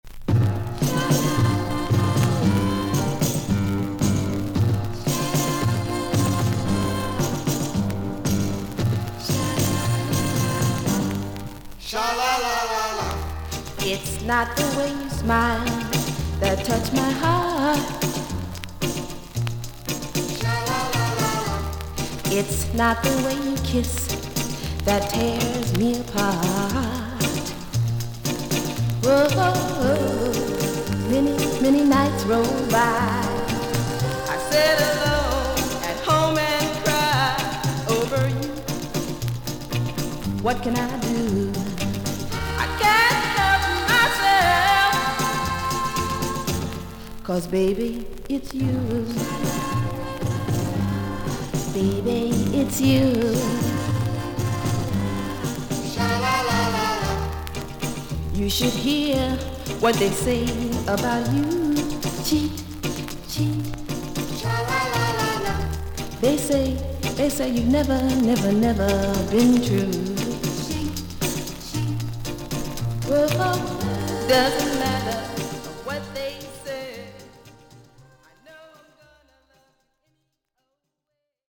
全体的に少々大きめのサーフィス・ノイズあり。両面ともイントロ部分はノイズが大きめです。少々軽いパチノイズの箇所あり。
ガール・グループ。